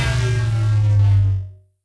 beam_off0.wav